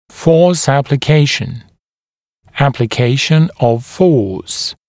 [fɔːs ˌæplɪ’keɪʃ(ə)n] [ˌæplɪ’keɪʃ(ə)n əv fɔːs][фо:с ˌэпли’кейш(э)н] [ˌэпли’кейш(э)н ов фо:с]приложение силы, применение силы